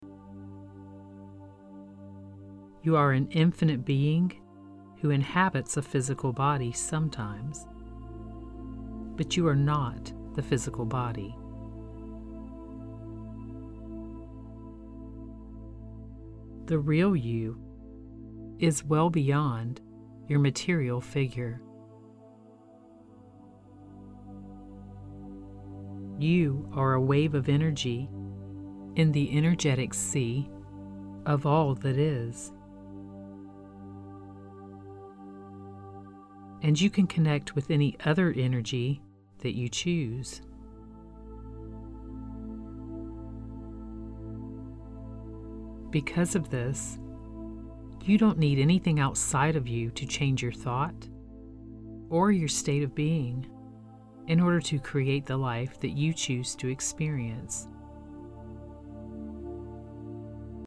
Activate the Quantum Field & MAGNETIZE What You Want Guided Meditation
Simply listen to my voice and let your mind and body follow the guidance to rewire in a new version of you who has what you want!